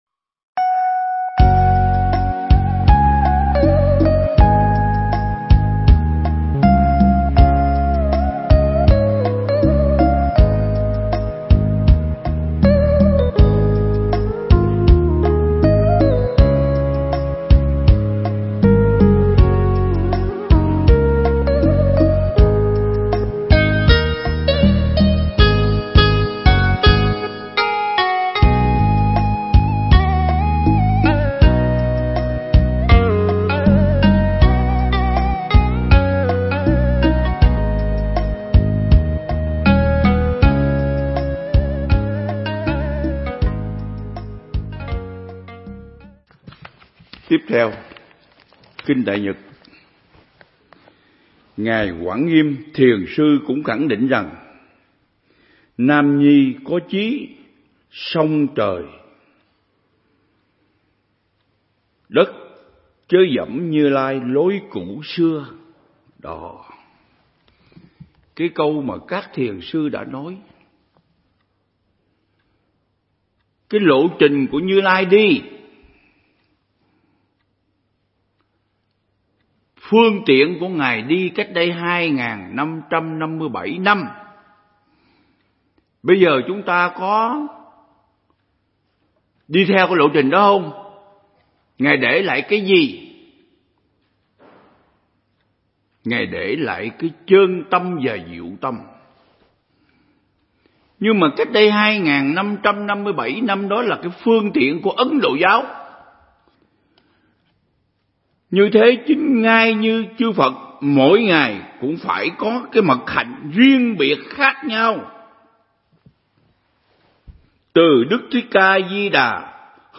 Pháp Thoại
giảng tại Viện Nghiên Cứu Và Ứng Dụng Buddha Yoga Việt Nam